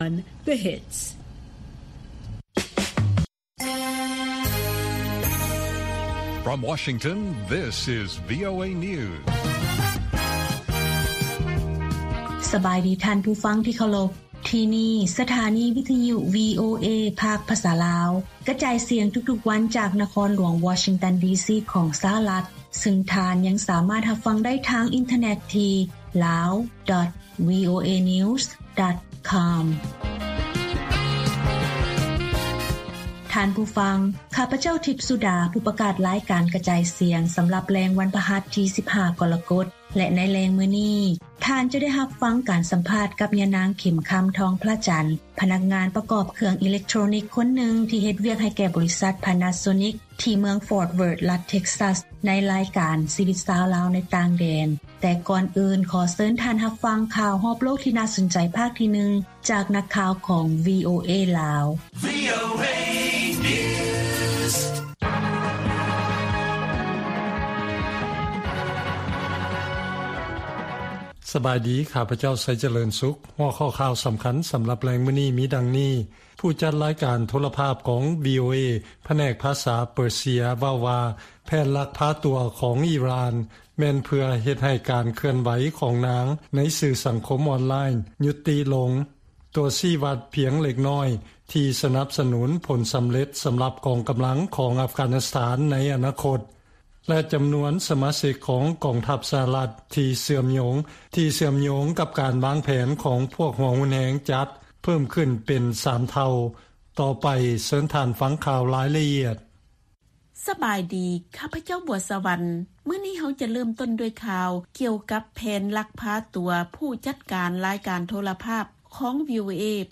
ວີໂອເອພາກພາສາລາວ ກະຈາຍສຽງທຸກໆວັນ. ຫົວຂໍ້ຂ່າວສໍາຄັນໃນມື້ນີ້ມີ: 1) ການເພີ່ມຂຶ້ນຂອງແນວຄິດຫົວຮຸນແຮງ ໃນກອງທັບສະຫະລັດເຊື່ອມໂຍງກັບເຫດການເຄື່ອນໄຫວທີ່ສຳຄັນ. 2) ຈີນສົ່ງນັກກິລາ ຫຼາຍກວ່າ 400 ຄົນ ໄປຮ່ວມການແຂ່ງຂັນໂອລິມປິກ ທີ່ໂຕກຽວ.